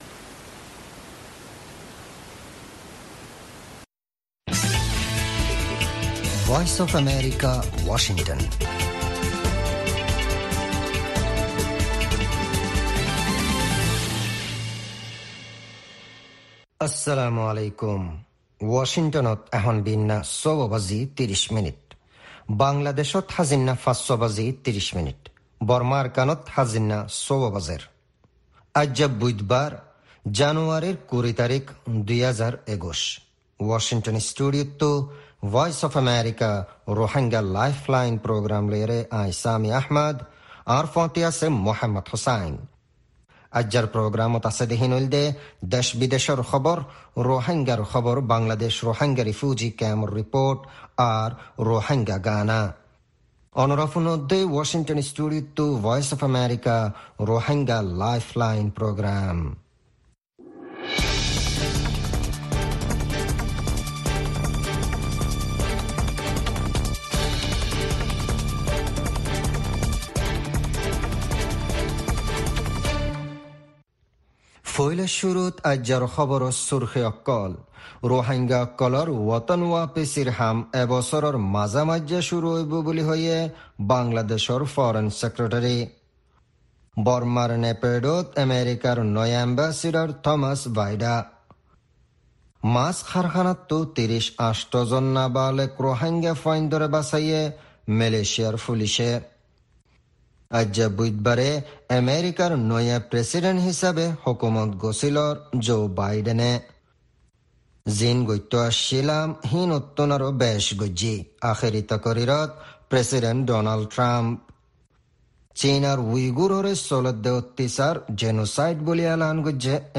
News Headlines